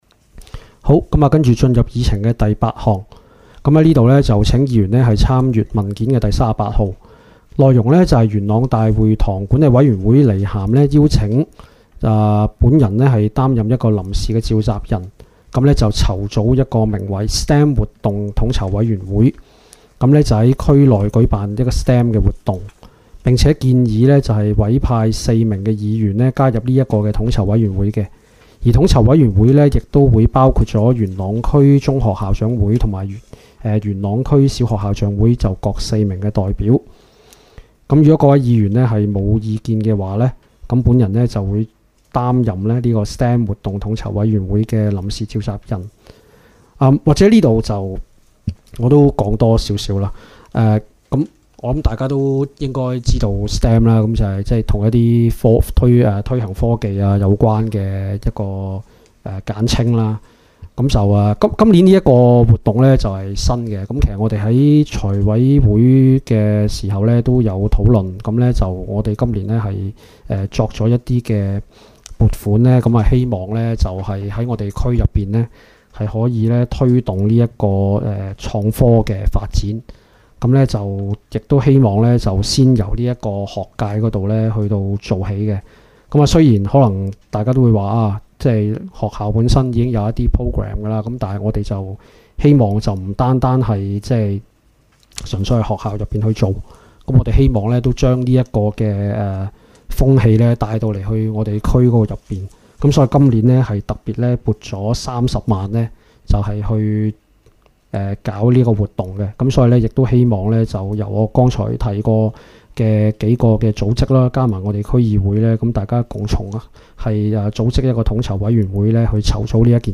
区议会大会的录音记录
地点: 元朗桥乐坊2号元朗政府合署十三楼会议厅